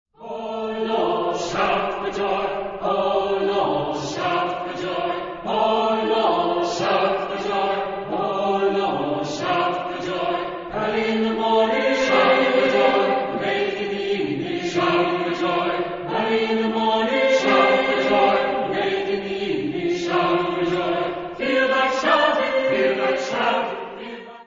Genre-Style-Forme : Sacré ; Spiritual Afro-Américain
Caractère de la pièce : exubérant
Type de choeur : SATB OU SSAA  (4 voix mixtes OU égales )
Tonalité : fa majeur